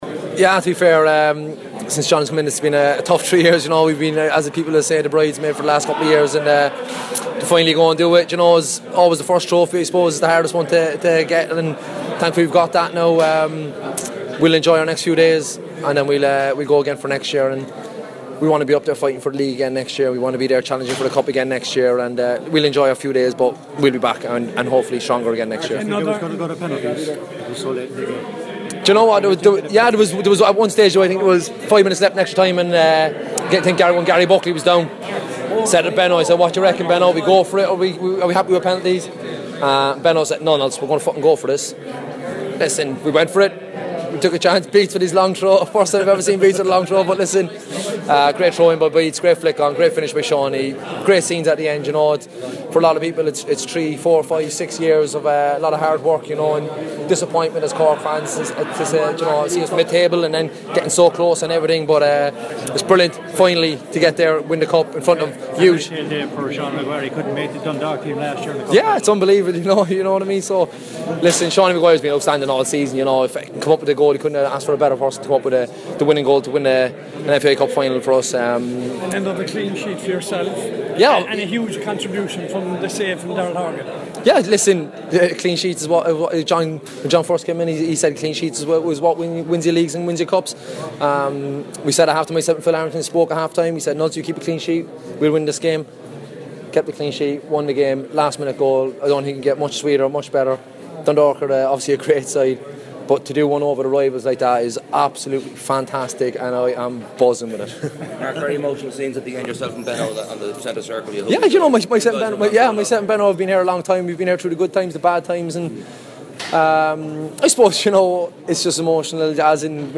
post match reaction